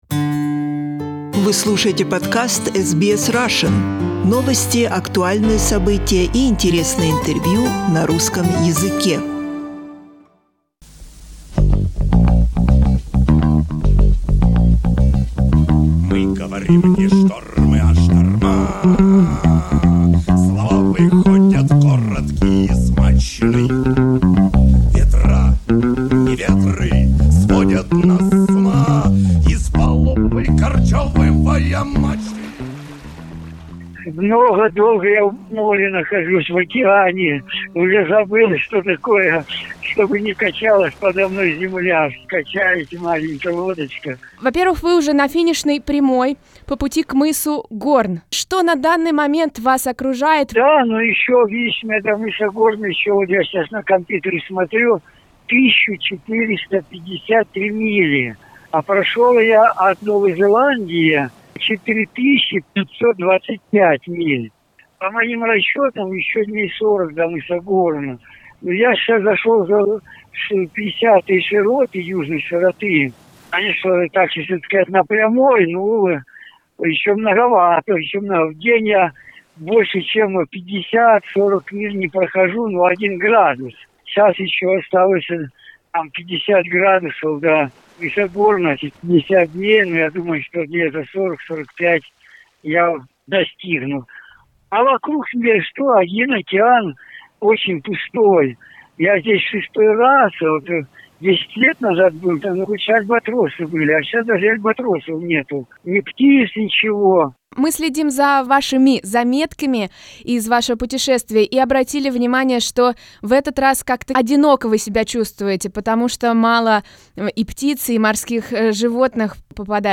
Fedor Konyukhov has passed the half way point of the first leg of his Round the world rowing trip and is now counting days before he reaches Cape Horn. Just before another change of the weather, we speak with the adventurer over the phone as he approaches the nigh of day 103.